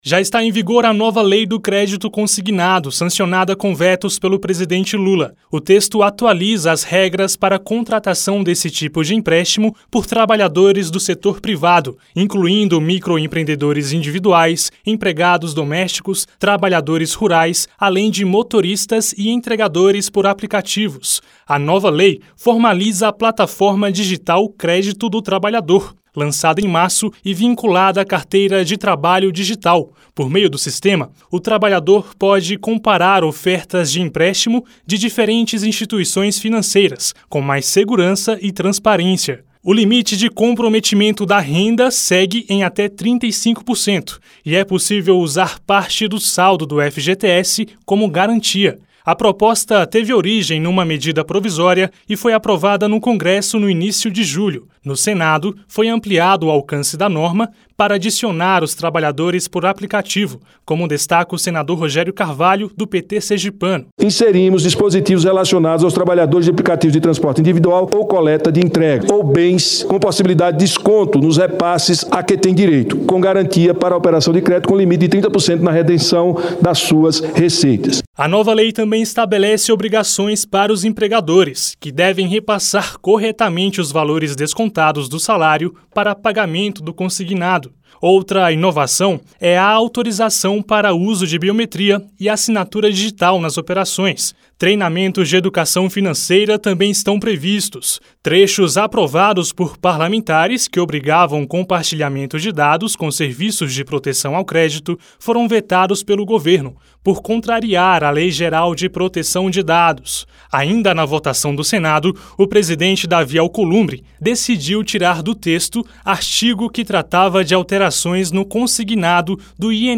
Senador Davi Alcolumbre
Senador Rogério Carvalho